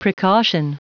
Prononciation du mot precaution en anglais (fichier audio)
Prononciation du mot : precaution